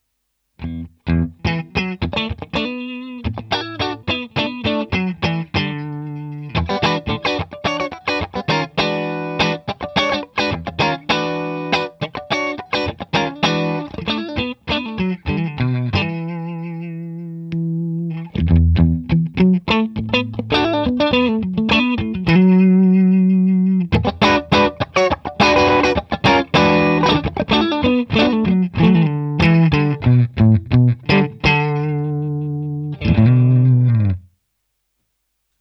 Der Clean-Sound überzeugt mich hier eher als beim AC.
Im ersten Klangbeispiel habe ich nach 17 Sekunden einen Clean-Boost hinzugeschaltet – das Resultat ist schon nah an der Vakuumröhre.
Clean via Sennheiser MD 421| mit Clean-Boost bei 0:17
vox_mv50_rock_test__clean_boost_md421.mp3